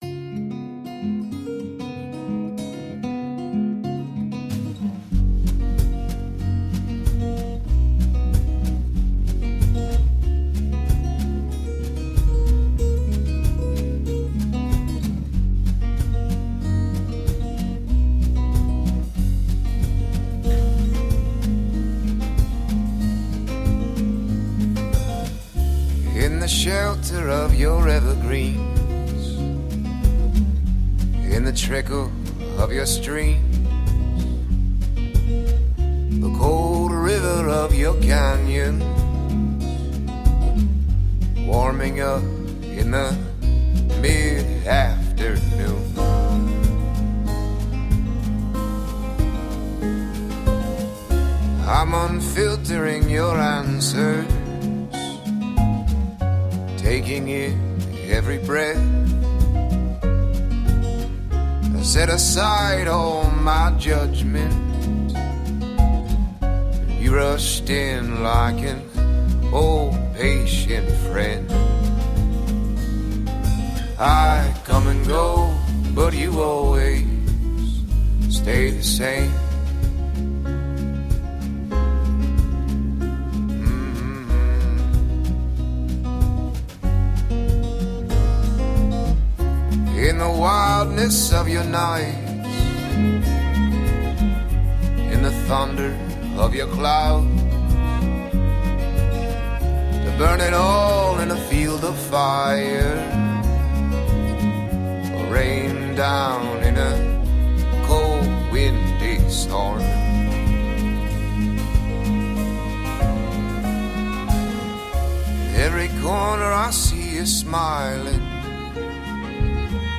Natural mix of rock, folk, and reggae.
is collection of folk songs